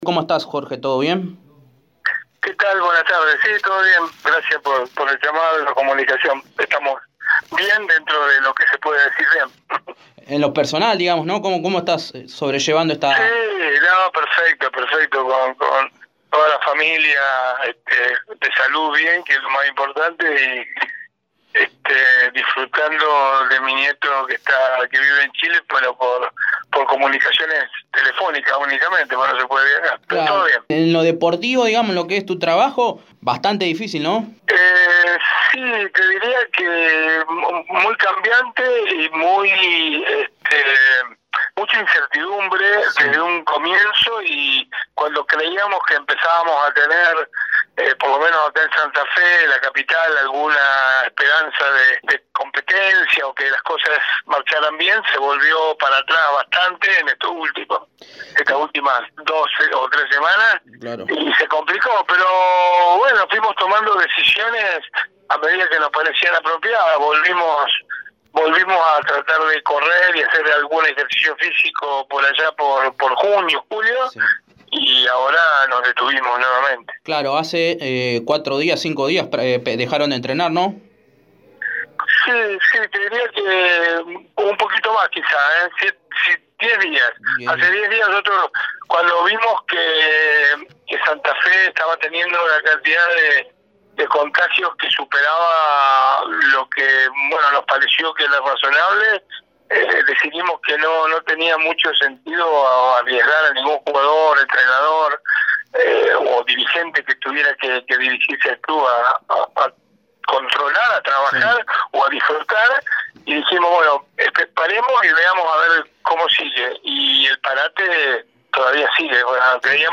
En diálogo con Radio EME Deportivo